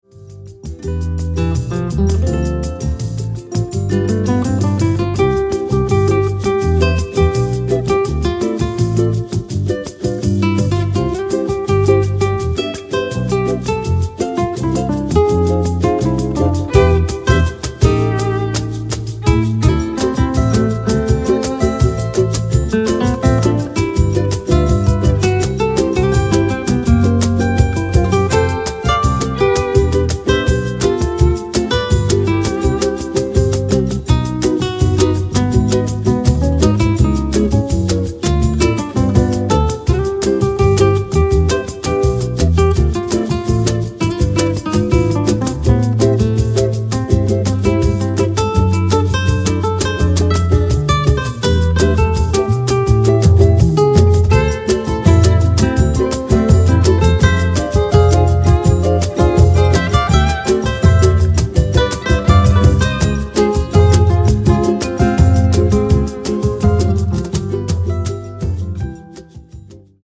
Nylon string melody on this bossanova tune.